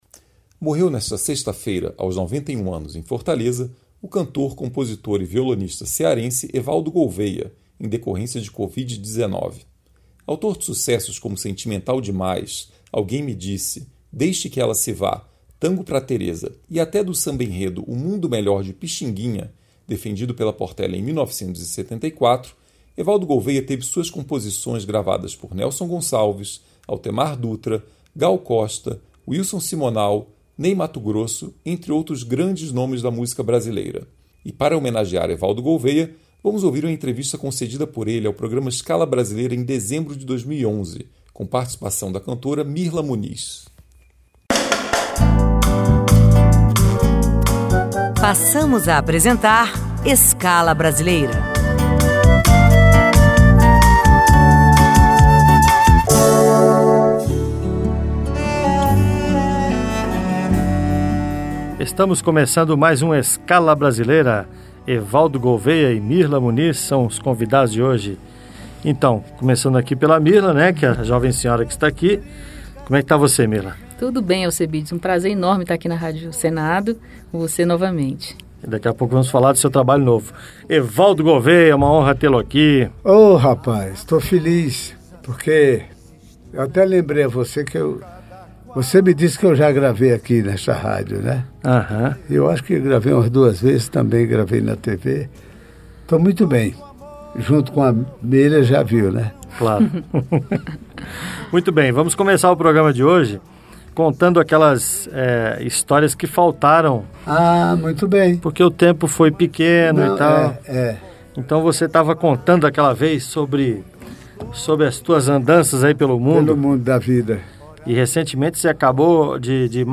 O cantor, compositor e violonista Evaldo Gouveia, que morreu na última sexta-feira (29), vítima de covid-19, concedeu algumas entrevistas para a Rádio Senado. Por duas vezes, Evaldo esteve nos estúdios da emissora